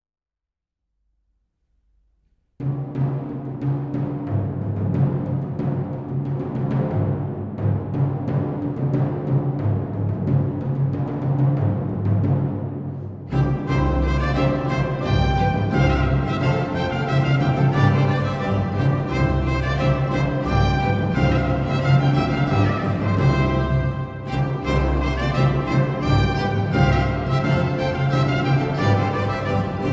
# Классика